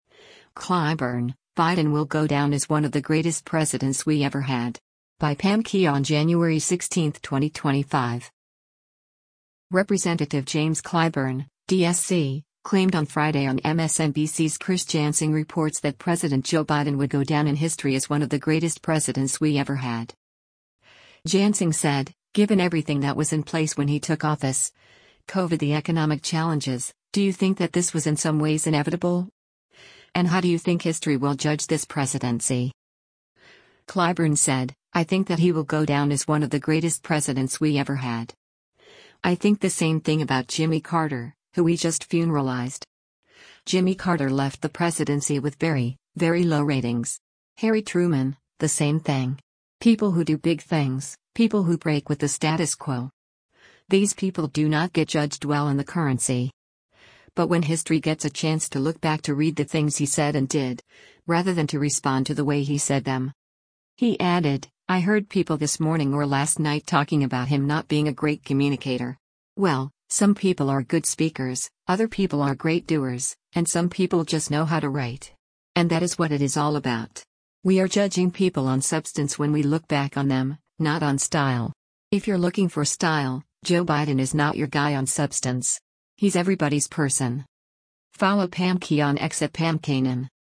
Representative James Clyburn (D-SC) claimed on Friday on MSNBC’s “Chris Jansing Reports” that President Joe Biden would go down in history as “one of the greatest presidents we ever had.”